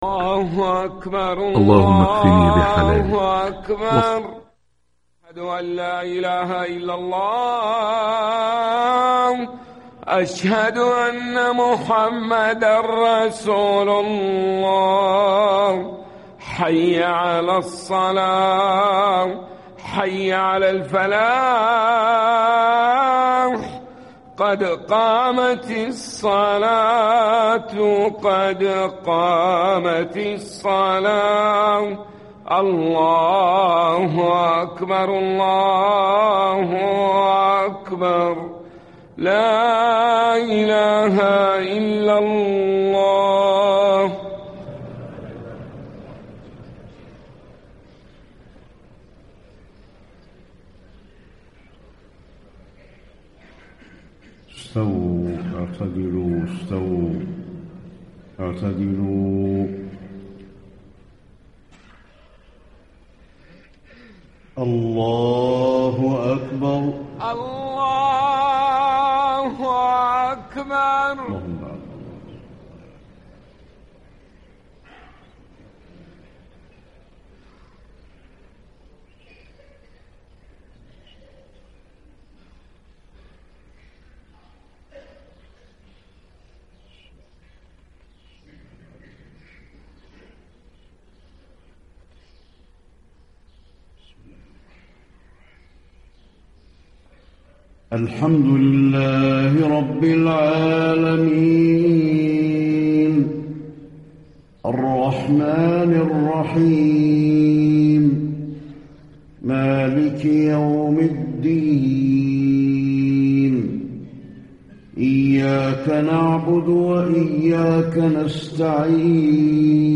صلاة الفجر 9-6- 1435 ما تيسر من سورة المؤمنون > 1435 🕌 > الفروض - تلاوات الحرمين